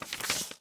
bookturn3.ogg